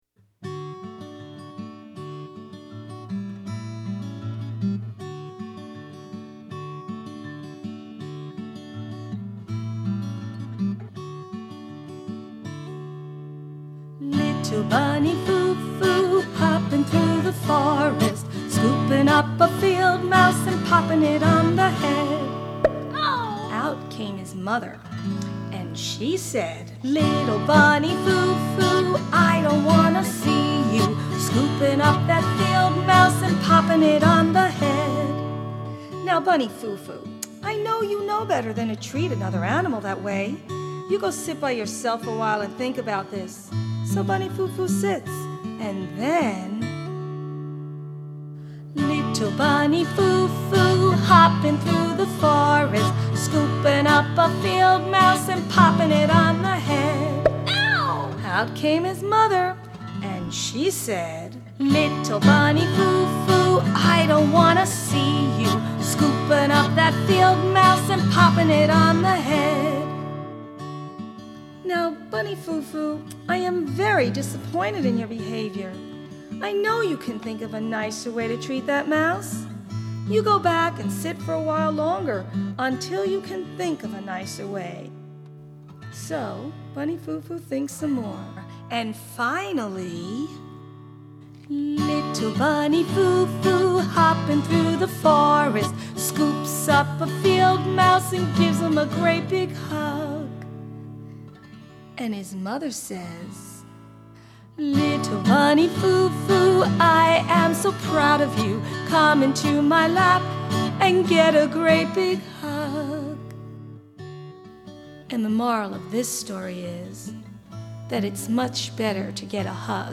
Genre: Childrens.